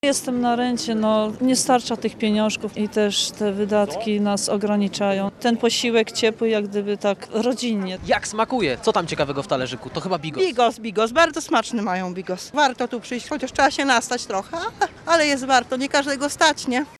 Z gośćmi wydarzenia rozmawiał nasz reporter.